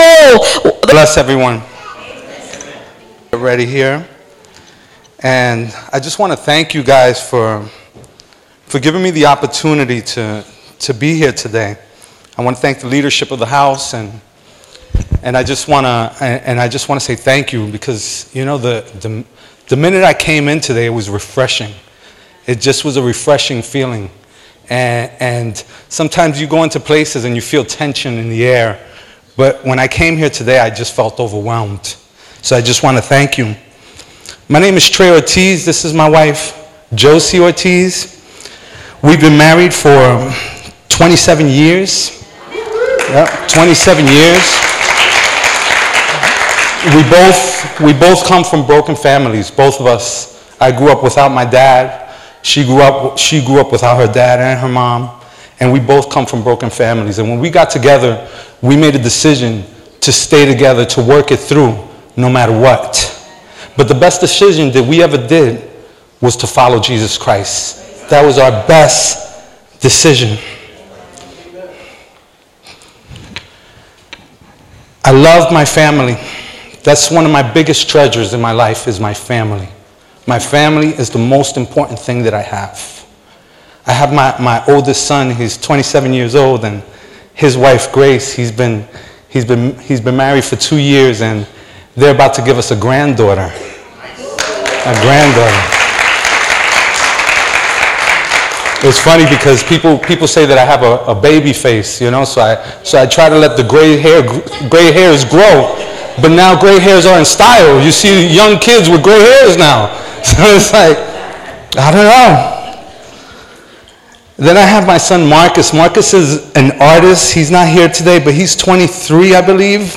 Love is all – West Hudson Christian Center